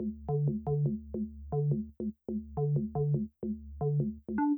000-bass.wav